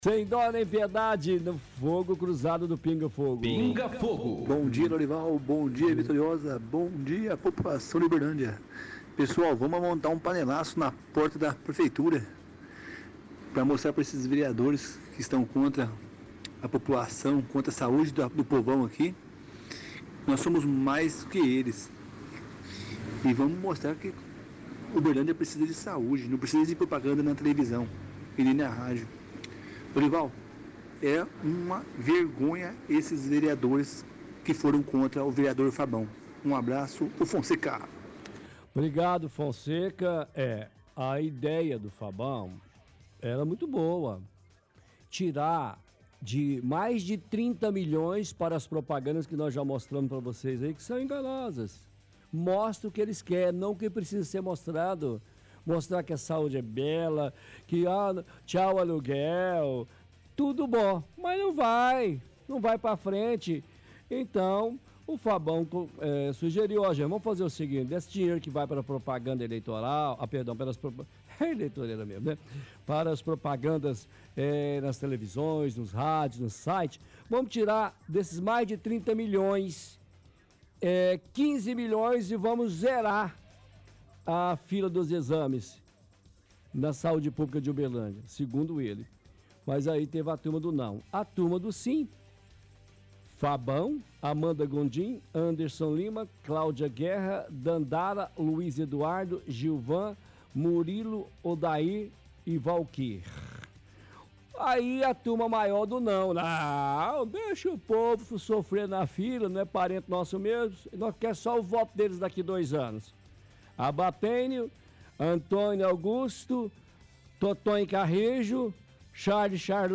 – Ouvinte sugere que a população faça um panelaço em frente a prefeitura para mostrar aos vereadores que a população não concorda com o voto contra a emenda do vereador Fabão.